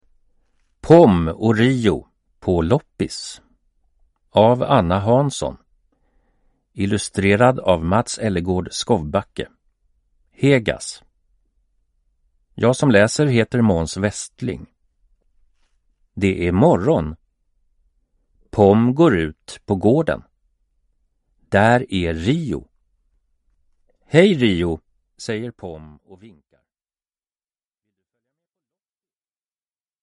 Pom och Rio - På loppis (ljudbok) av Anna Hansson